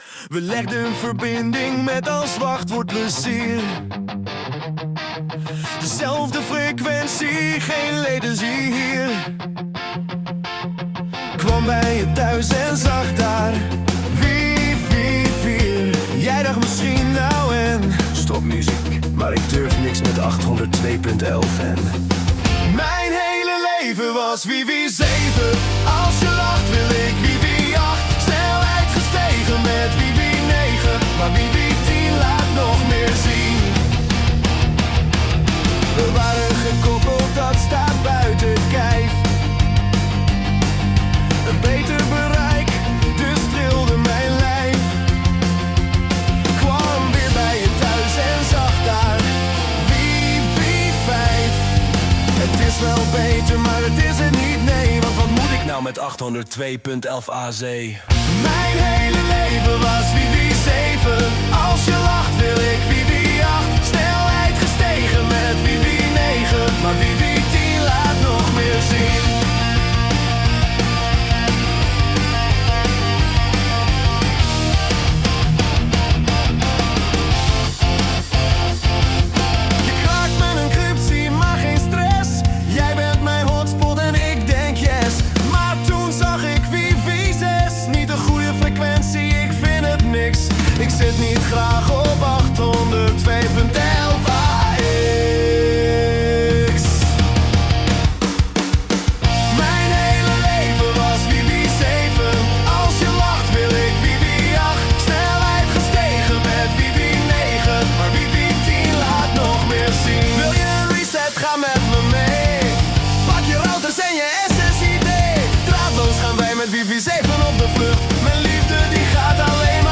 Dus je kunt neerzetten ‘wifi’, maar dat spreekt de zangstem dan raar uit, als 'waifi' of 'wiffie' bijvoorbeeld.
versie met wat meer gitaren voor de liefhebber, hoewel het
Tweakers - Mijn hele leven was Wifi 7 (gitaarversie).mp3